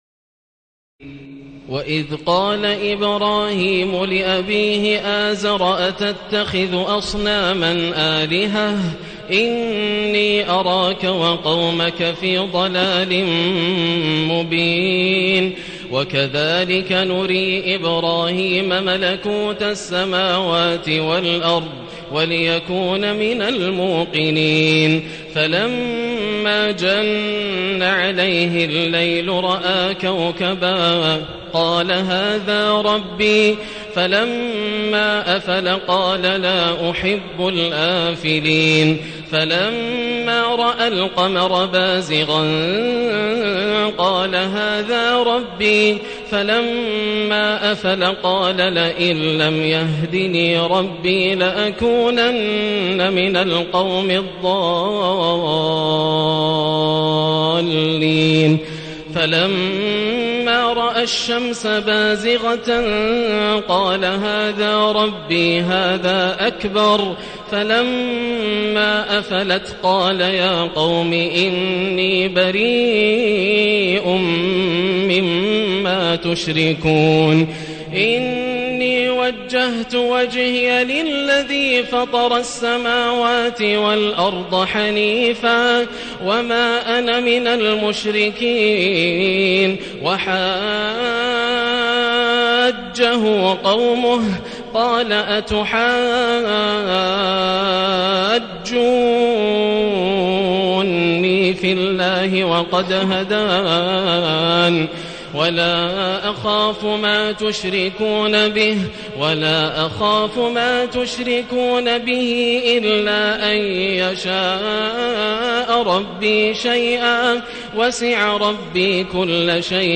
تراويح الليلة السابعة رمضان 1437هـ من سورة الأنعام (74-144) Taraweeh 7 st night Ramadan 1437H from Surah Al-An’aam > تراويح الحرم المكي عام 1437 🕋 > التراويح - تلاوات الحرمين